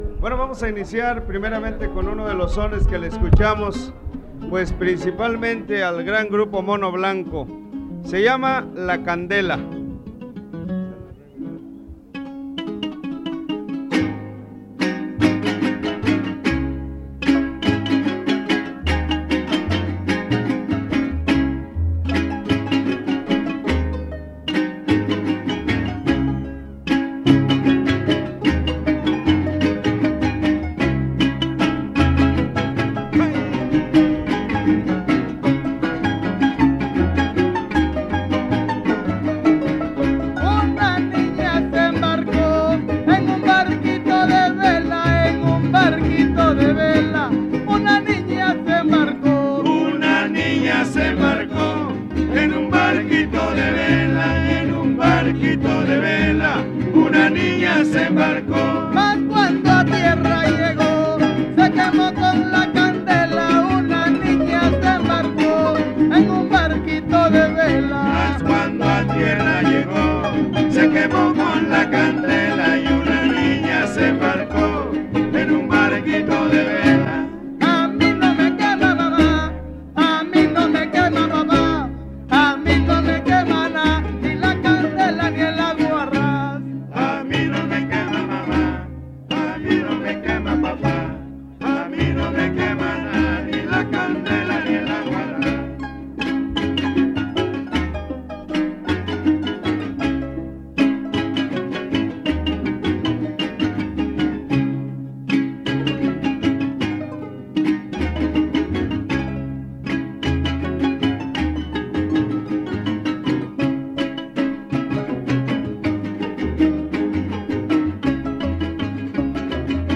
• Siquisirí (Grupo musical)
Noveno Encuentro de jaraneros